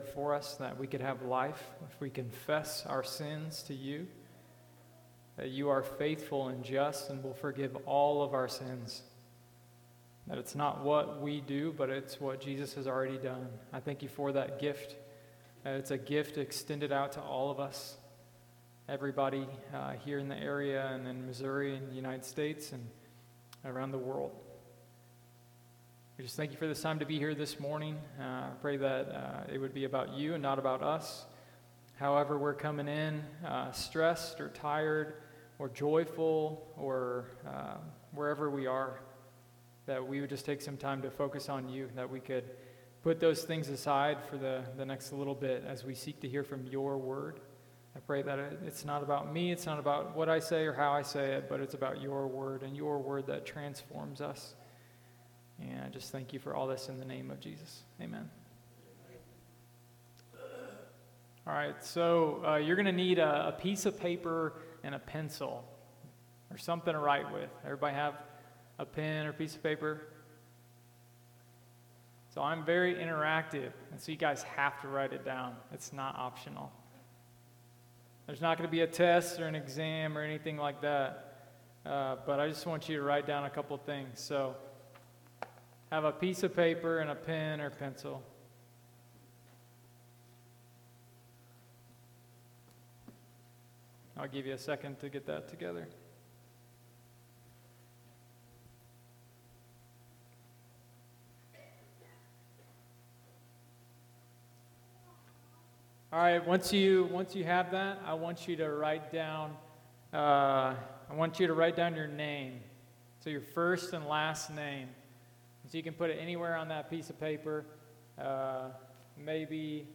August-6-2023-morning-service.mp3